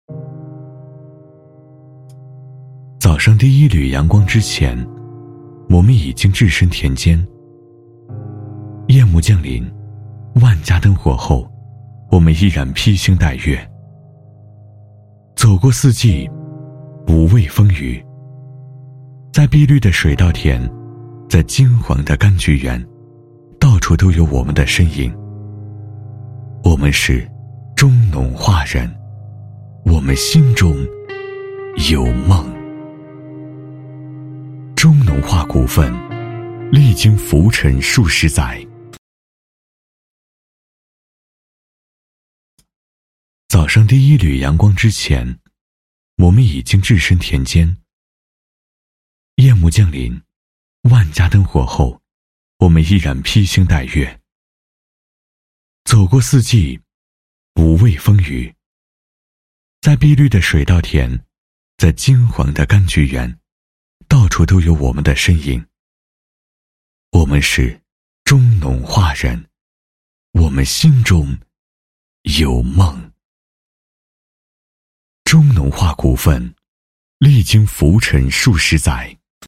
Chinese (Mandarin)
Authoritative Friendly Engaging